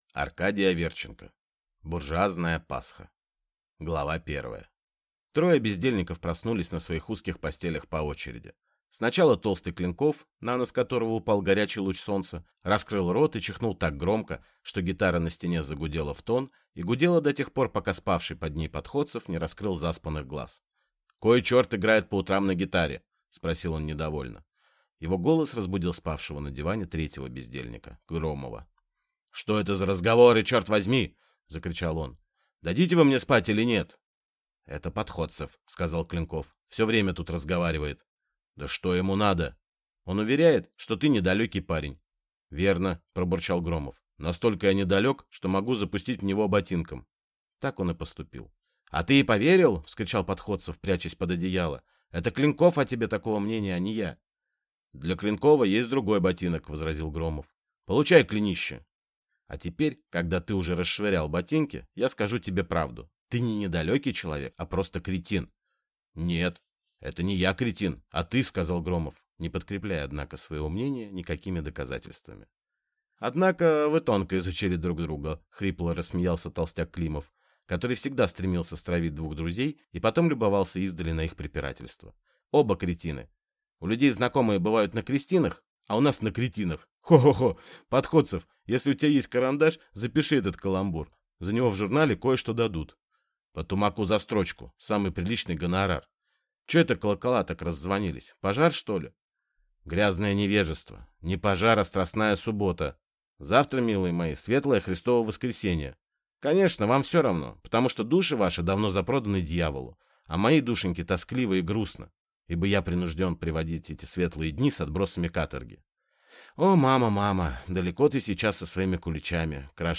Аудиокнига Буржуазная Пасха | Библиотека аудиокниг
Прослушать и бесплатно скачать фрагмент аудиокниги